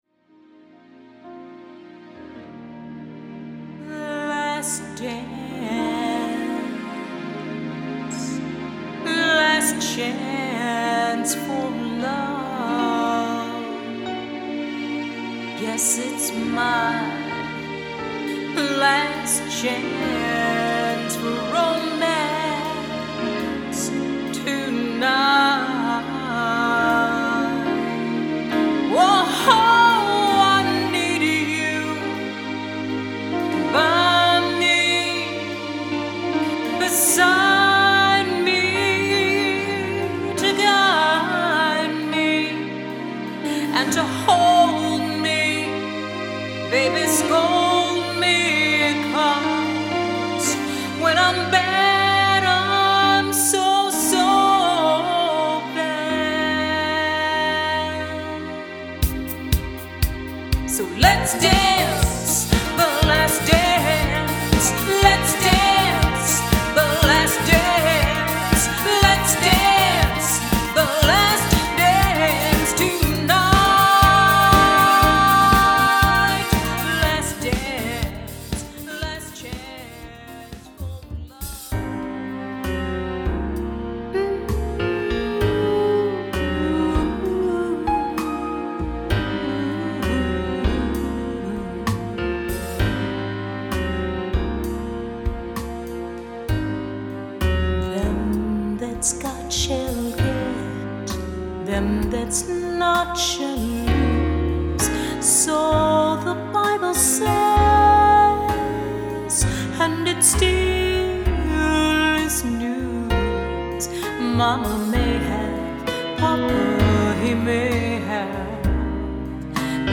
Wedding Singer
Sample Medley